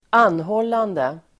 Uttal: [²'an:hål:ande]